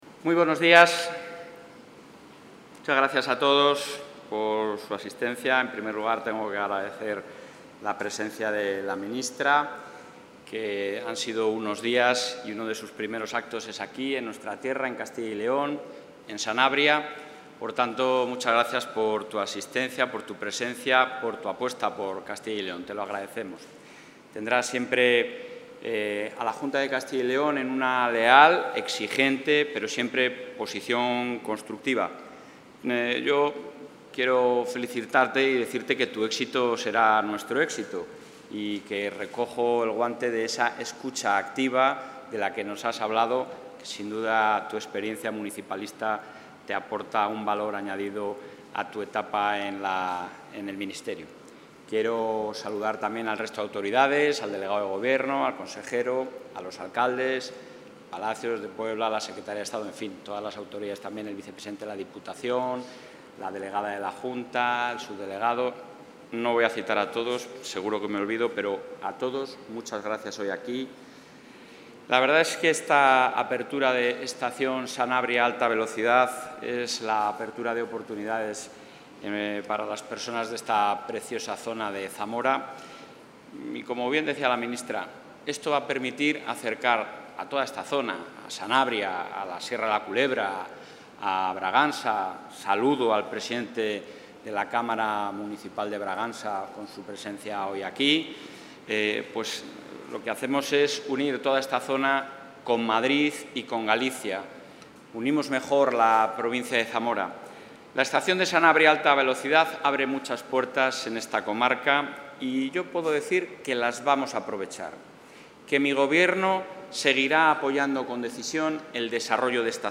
El presidente de la Junta de Castilla y León, ha asistido esta mañana al acto de puesta en servicio de la estación de Sanabria...
Intervención del presidente de la Junta.